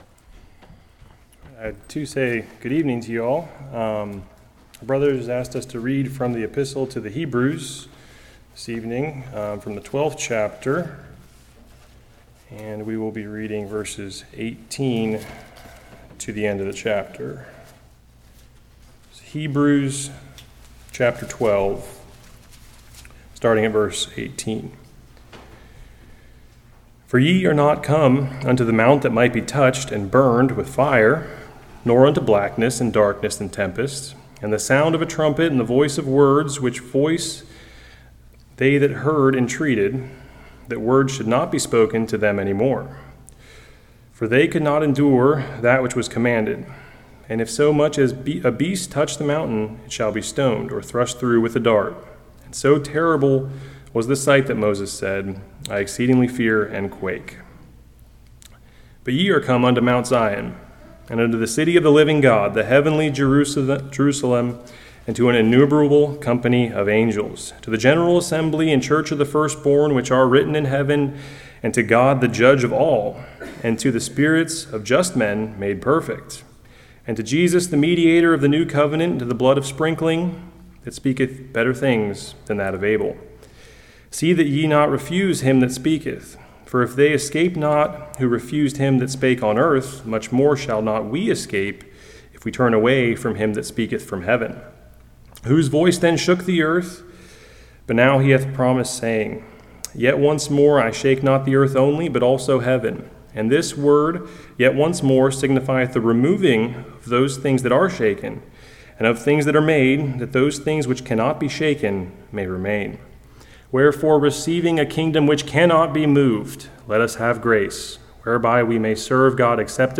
Hebrews 12:18-29 Service Type: Evening Believers have received a kingdom which cannot be moved!